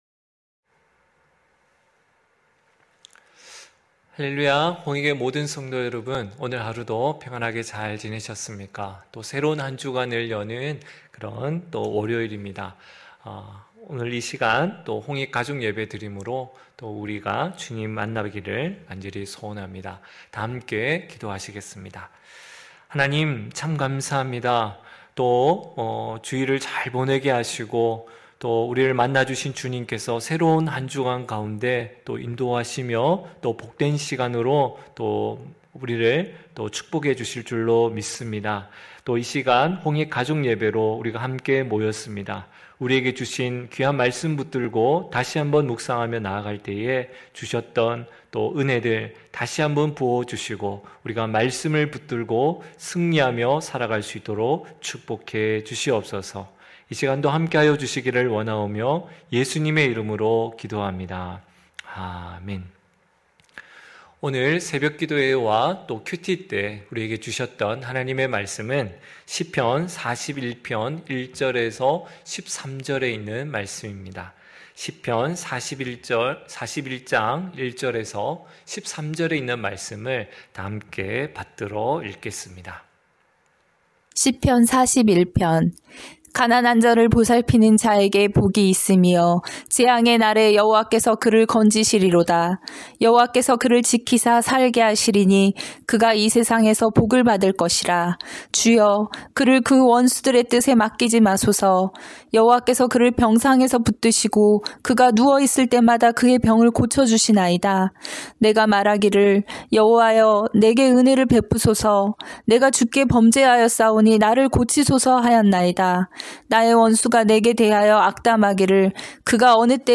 9시홍익가족예배(12월28일).mp3